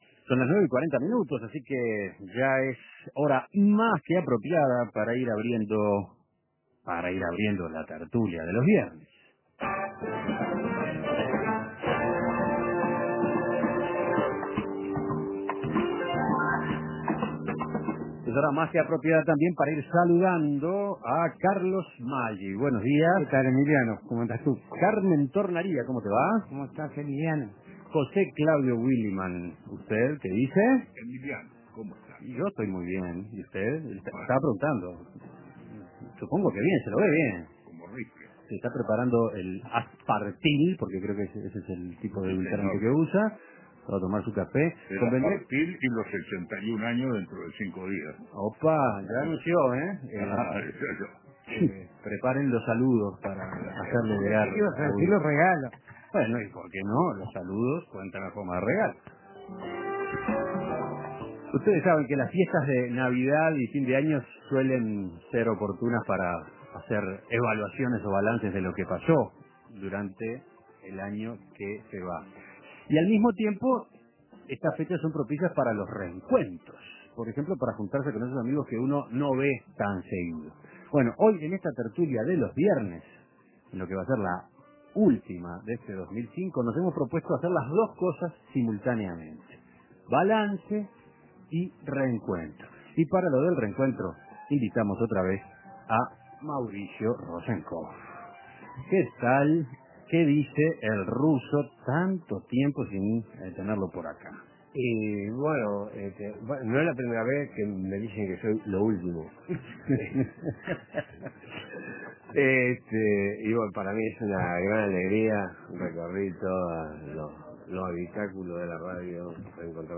Balance con invitado. Junto a Mauricio Rosencof, los contertulios de los viernes repasan las "buenas noticias" del año que termina.